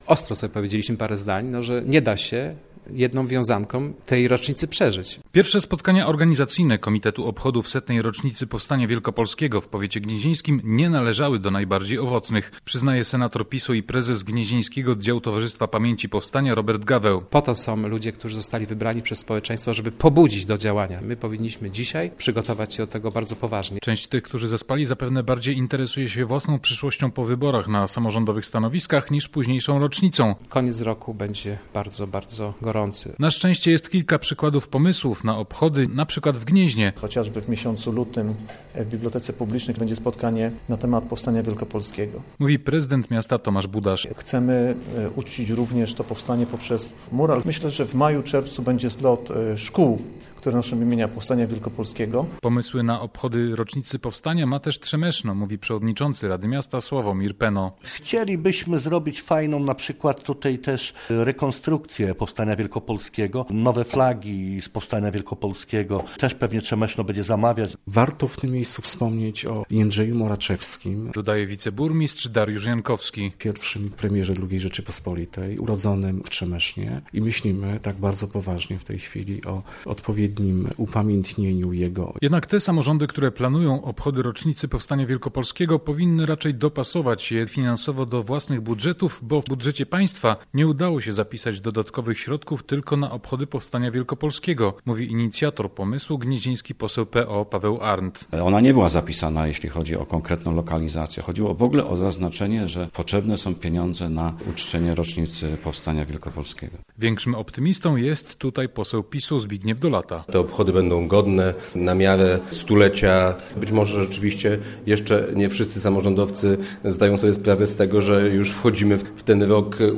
O tym w relacji